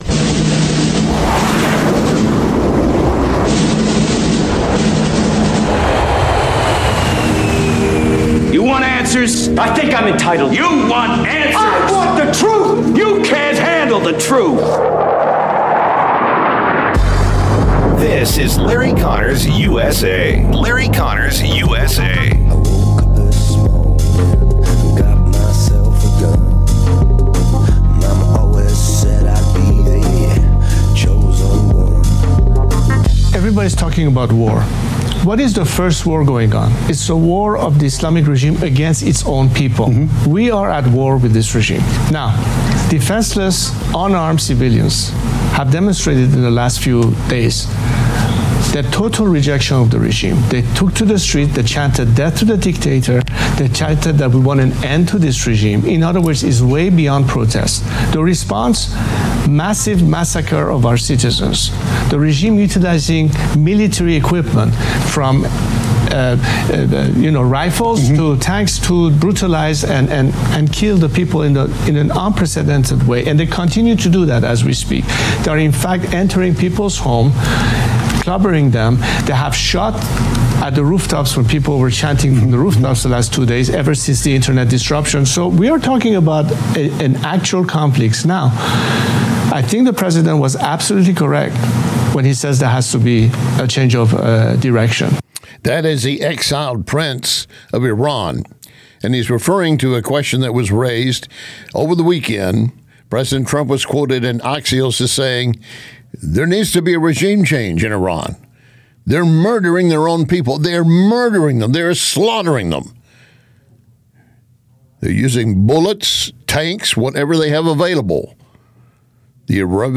Guest: Gordon G. Chang